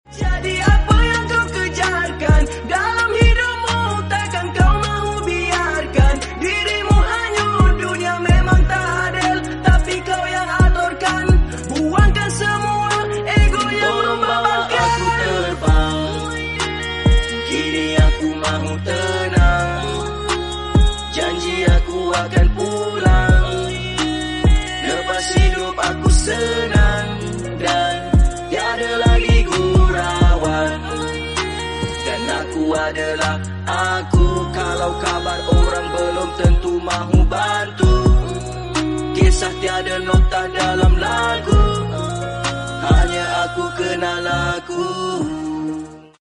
Proses penyambungan Polypipe 1 inch sound effects free download